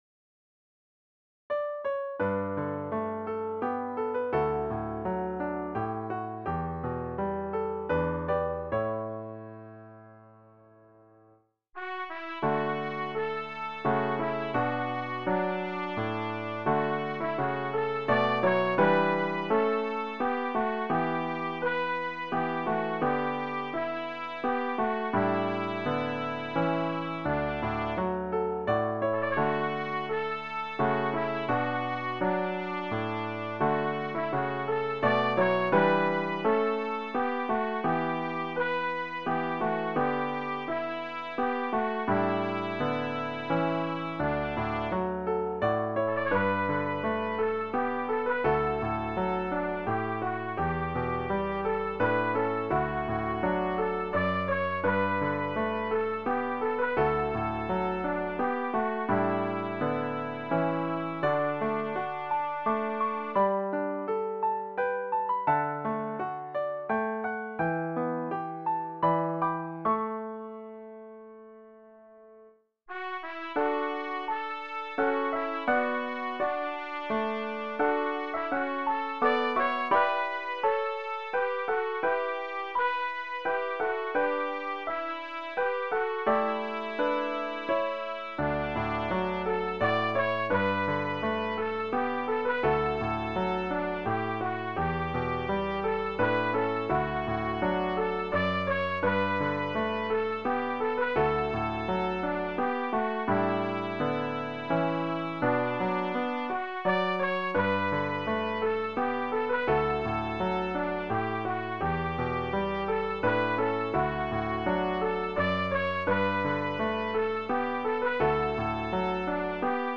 Instead of verse/chorus, verse/chorus, verse chorus, it has been rearranged to verse, verse, chorus, verse, chorus, chorus. Some of the accompaniament has been designated as 8va, with a few surprise chords towards the end. The accompaniament remains basically the same as the original hymn and also in the same key.
Voicing/Instrumentation: Vocal Solo We also have other 86 arrangements of " Away In a Manger ".